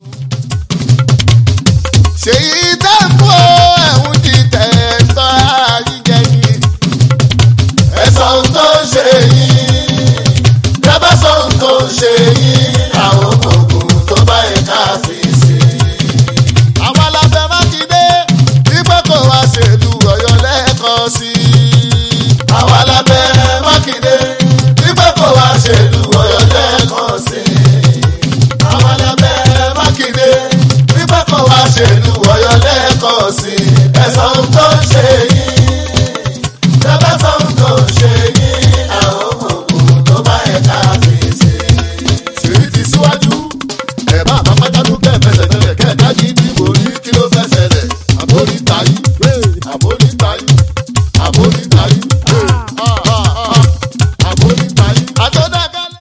FUJI 男声